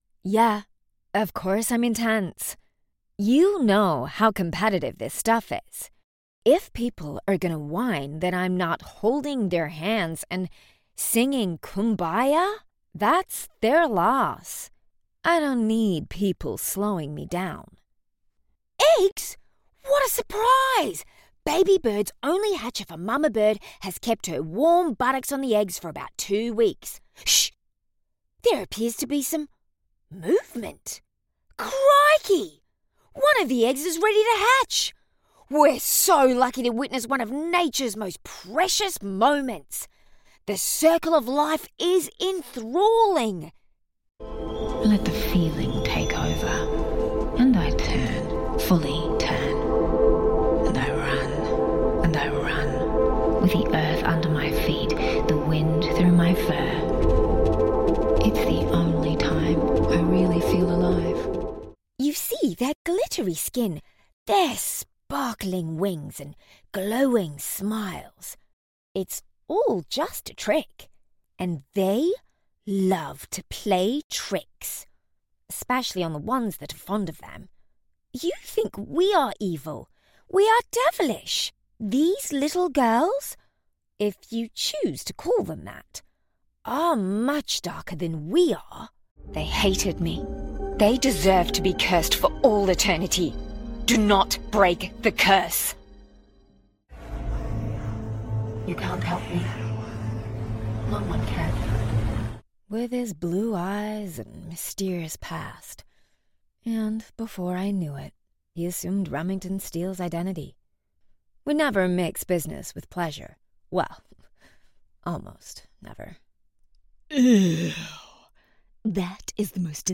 Female
English (Australian)
Character / Cartoon
Character Work